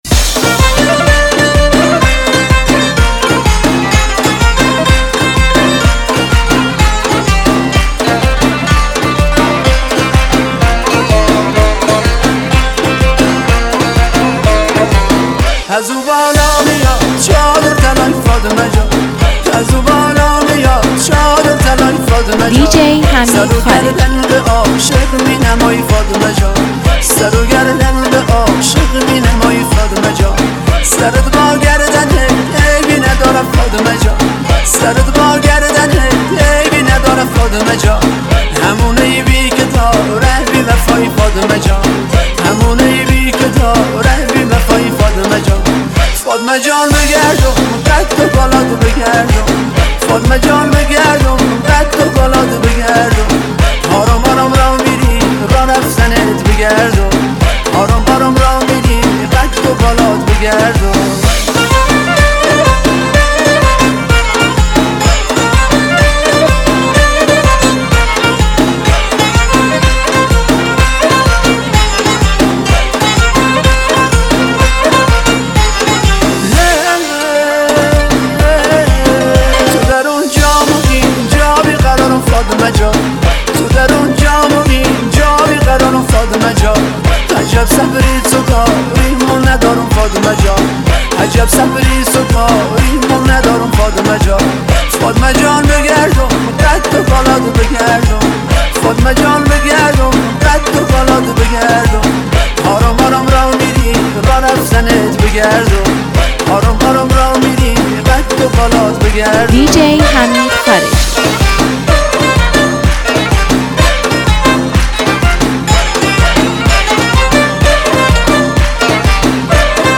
انفجار انرژی